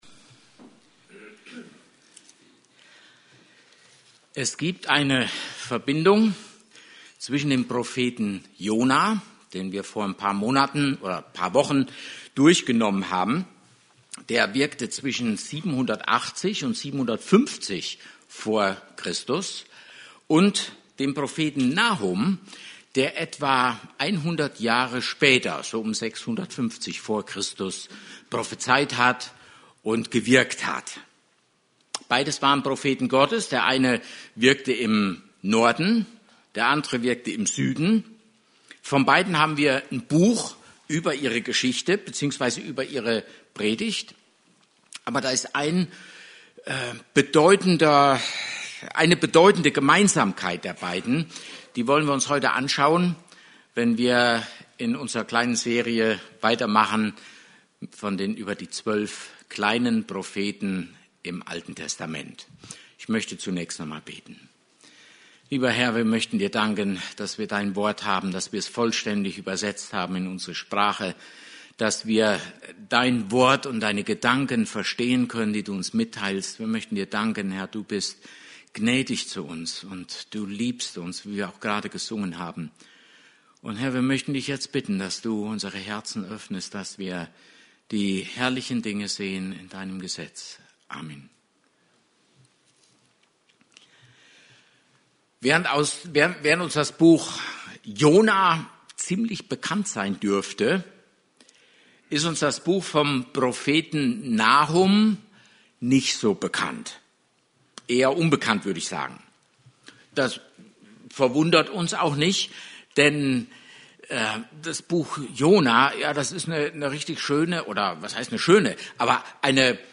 Service Type: Gottesdienst